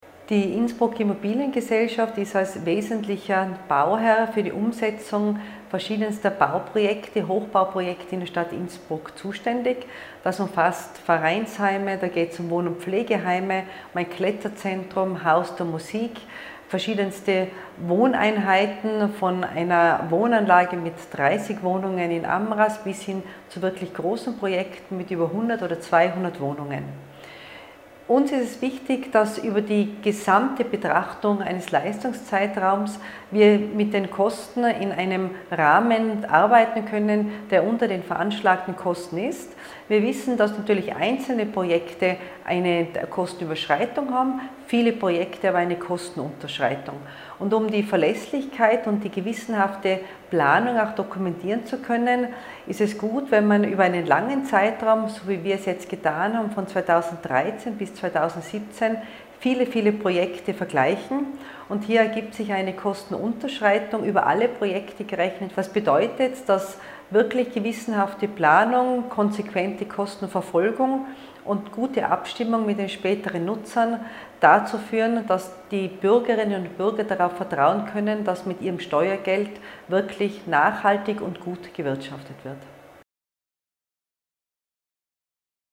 OT von Bürgermeisterin Christine Oppitz-Plörer